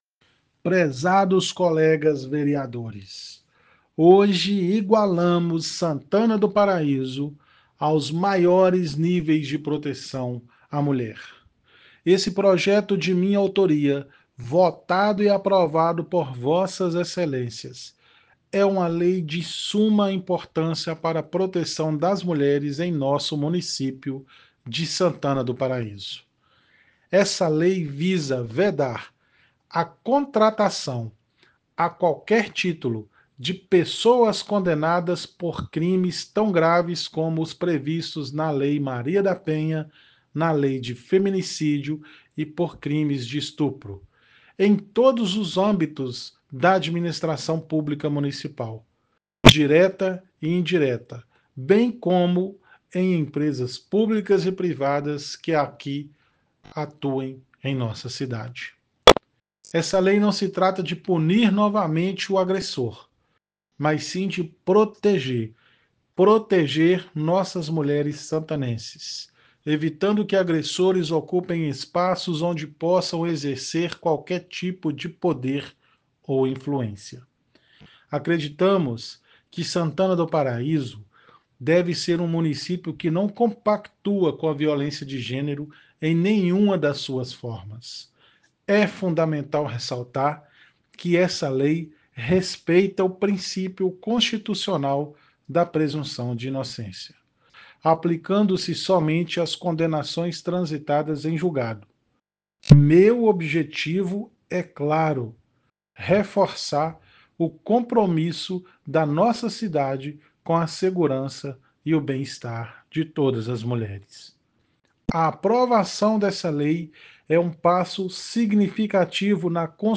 Ouça o que o vereador Wander da Civil falou sobre o PL: